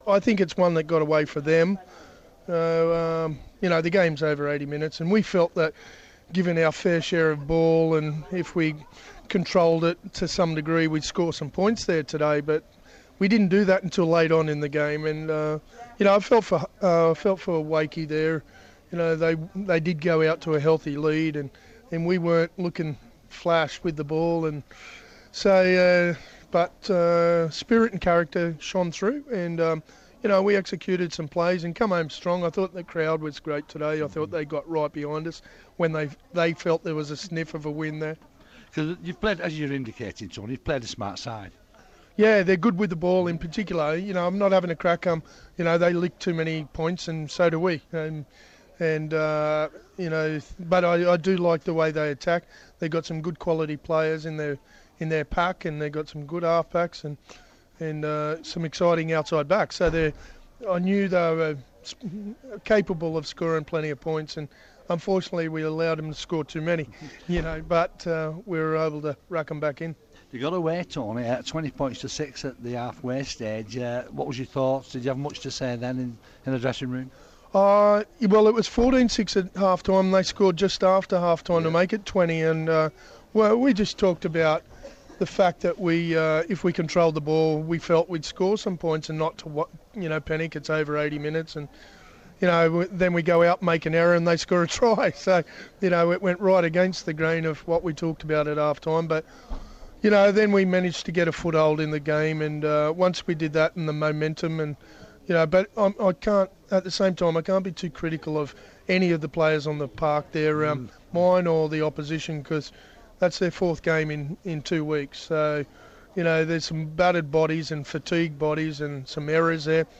Warrington coach Tony Smith reacts to the narrow win over Wakefield.